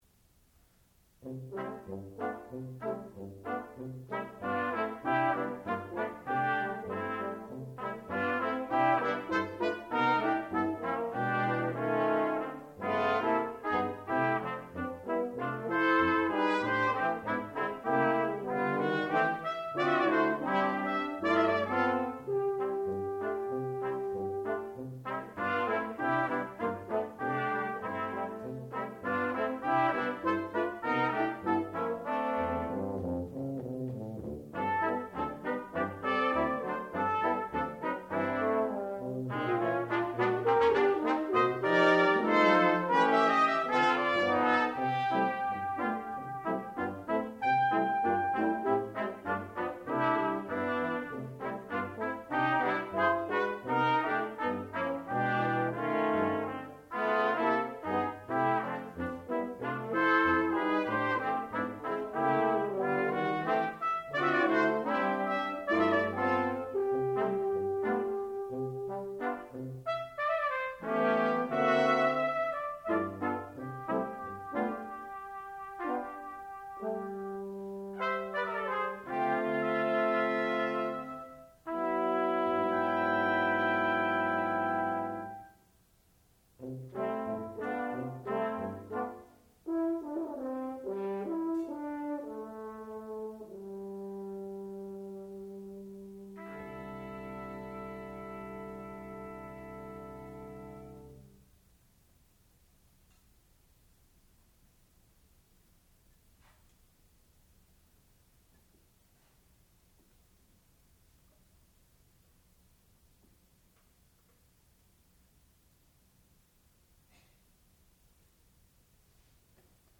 sound recording-musical
classical music
trumpet
tuba